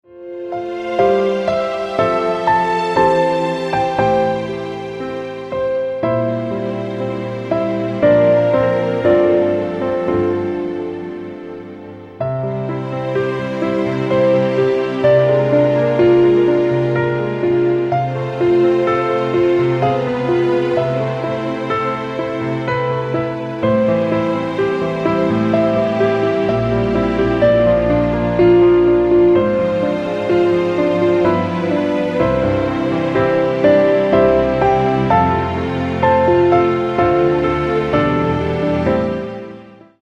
Piano - Strings - Medium